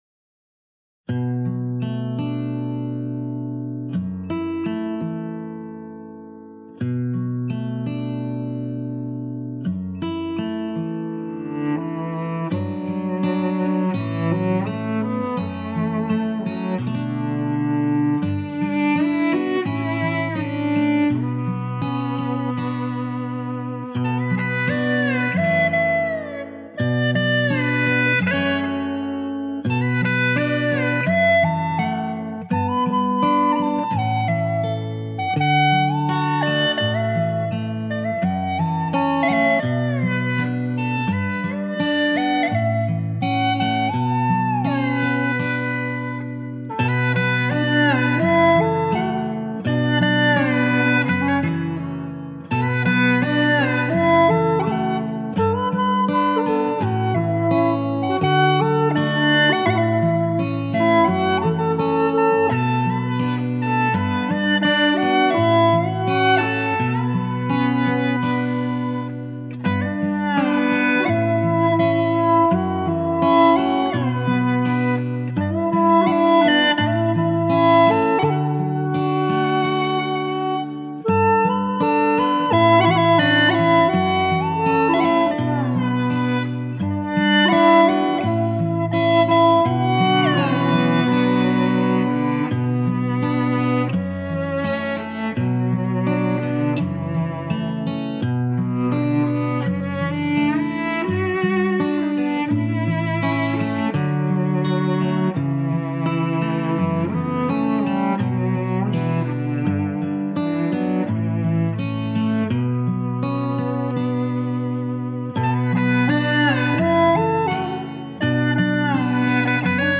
调式 : D 曲类 : 流行